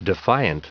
Prononciation du mot defiant en anglais (fichier audio)
Prononciation du mot : defiant